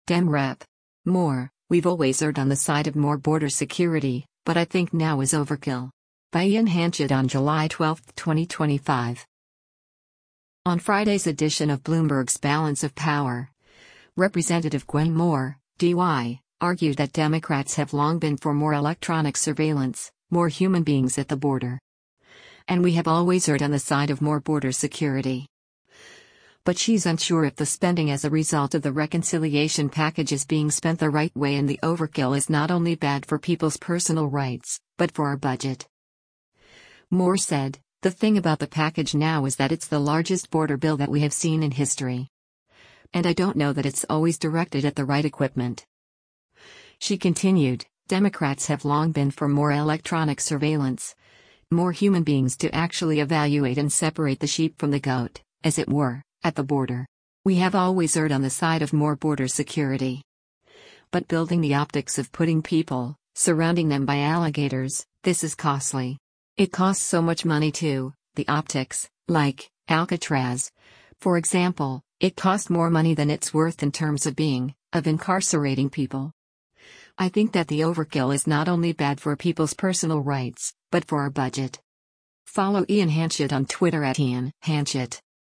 On Friday’s edition of Bloomberg’s “Balance of Power,” Rep. Gwen Moore (D-WI) argued that “Democrats have long been for more electronic surveillance, more human beings” at the border.